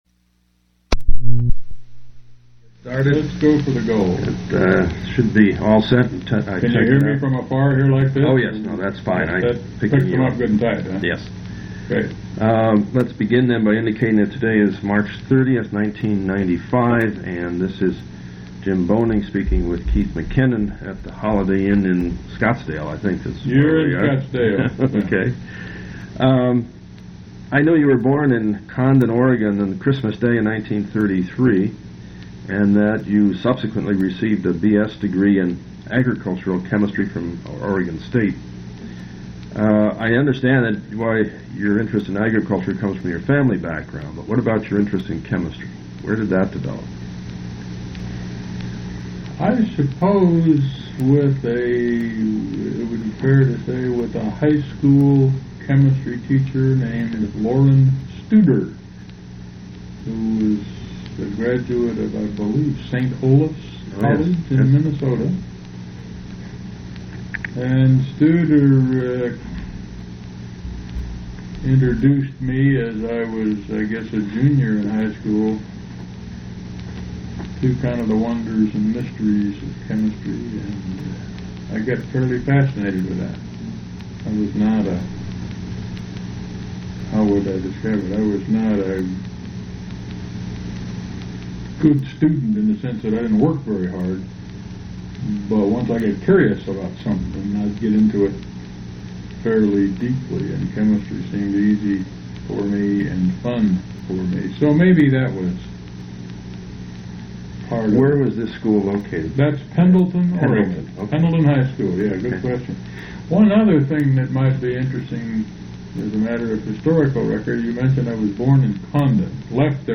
Oral histories
Place of interview Arizona--Scottsdale